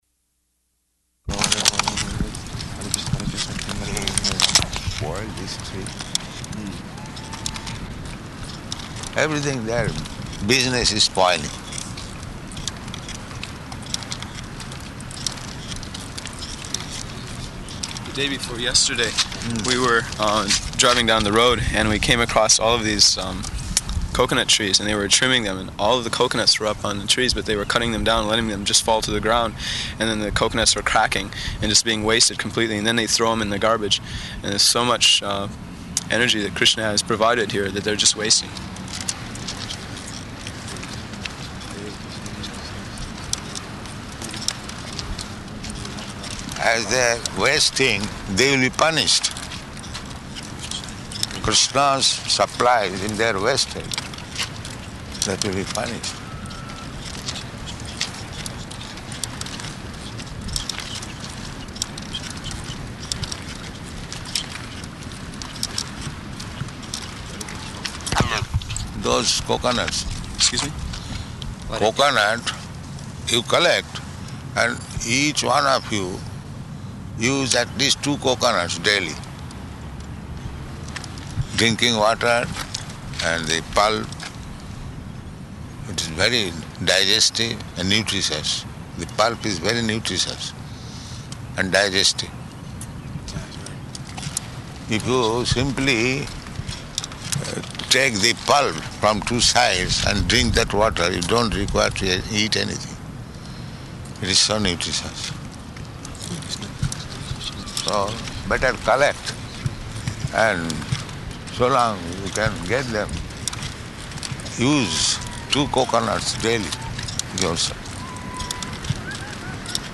Morning Walk, partially recorded
Type: Walk
Location: Honolulu